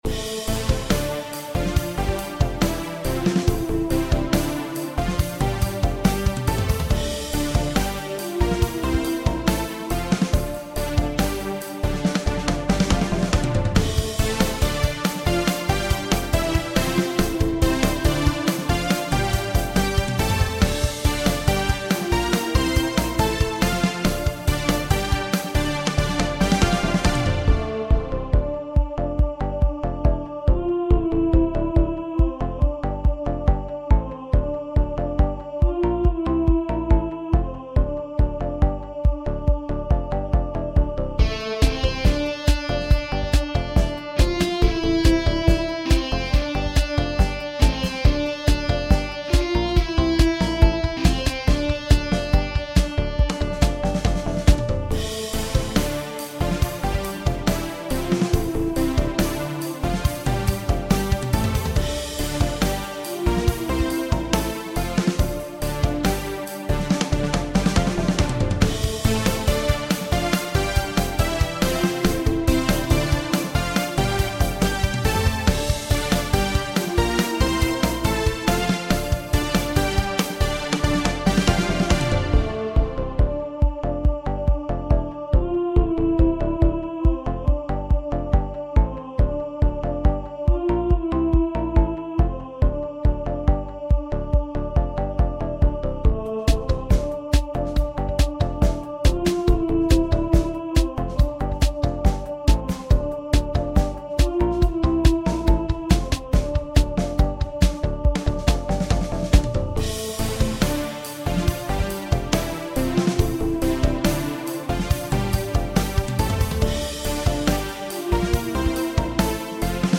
Dramatic boss music! Seamlessly looping track.